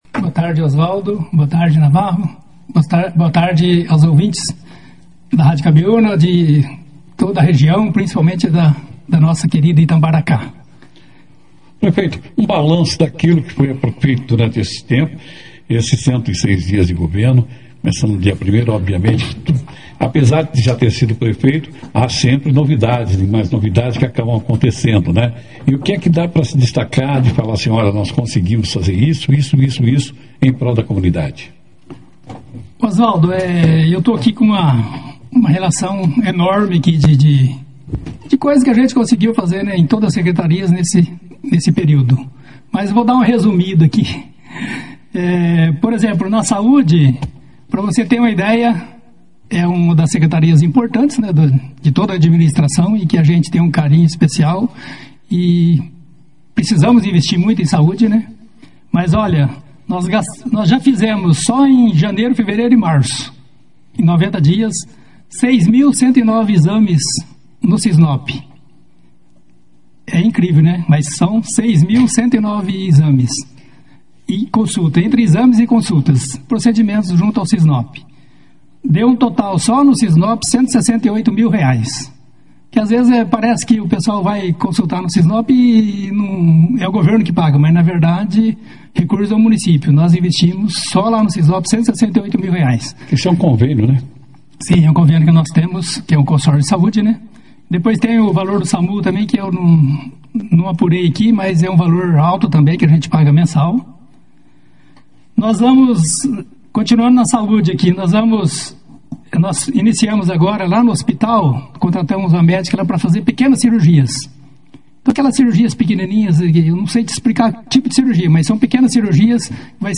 Durante participação na 2ª edição do Jornal Operação Cidade desta quarta-feira (16), o prefeito de Itambaracá, Amarildo Tostes,(foto), fez um balanço dos primeiros 106 dias de sua gestão. Na entrevista, ele destacou ações voltadas para a melhoria do atendimento à população, com investimentos significativos em saúde, geração de empregos, retomada do esporte regional e fortalecimento dos conselhos municipais.